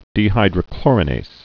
(dē-hīdrə-klôrə-nās, -nāz)